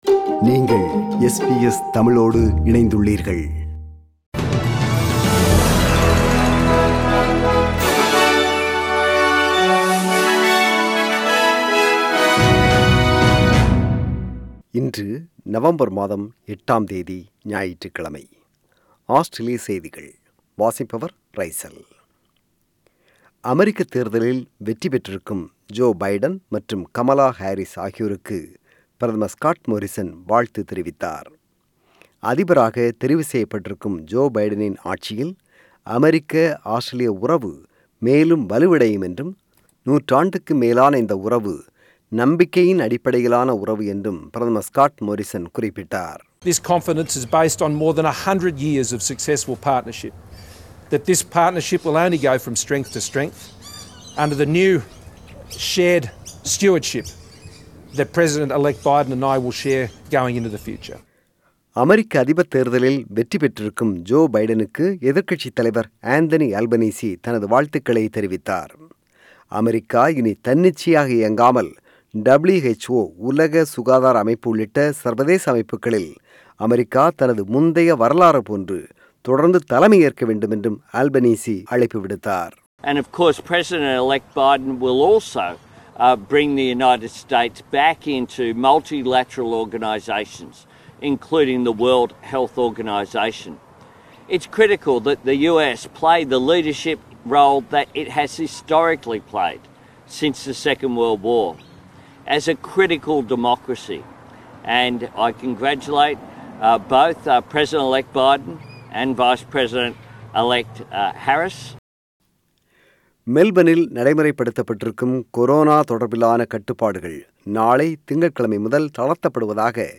The news bulletin of SBS Tamil on 8 November 2020 (Sunday).